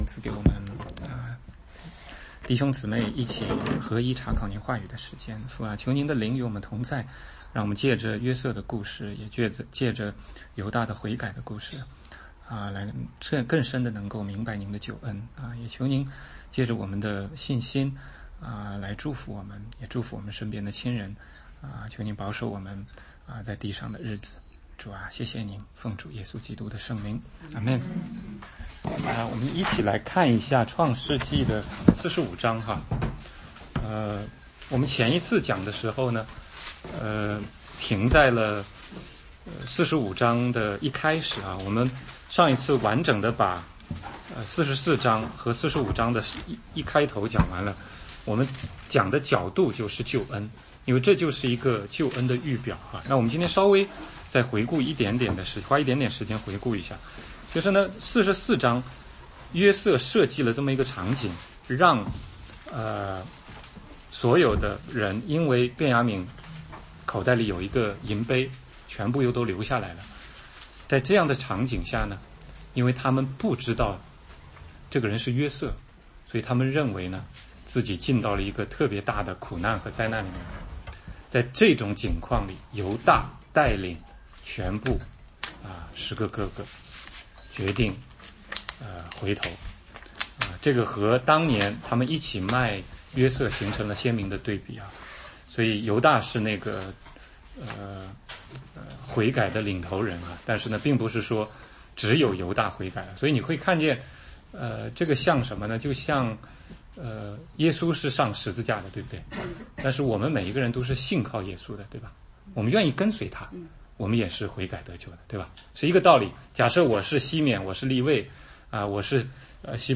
16街讲道录音 - 创世纪45:6-15 怎么样看环境？在环境里看见神的旨意，神的带领？